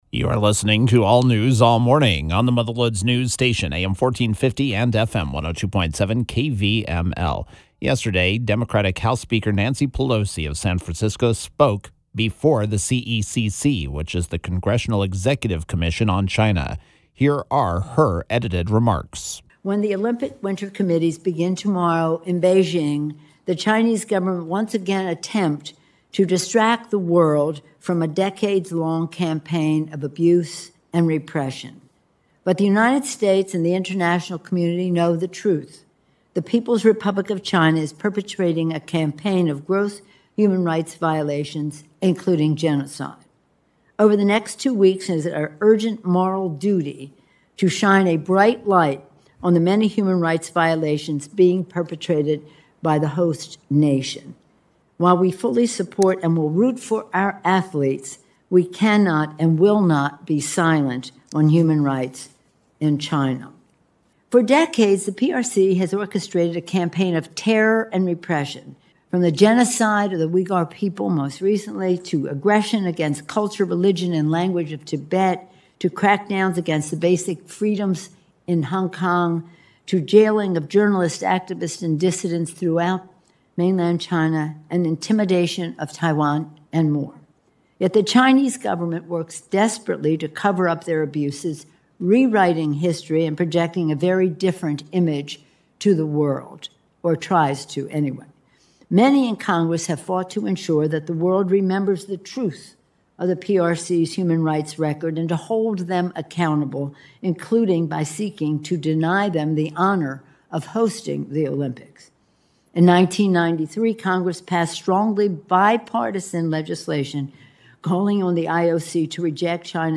House Speaker Nancy Pelosi spoke against China’s human rights abuses before the Congressional Executive Commission on China (The CECC).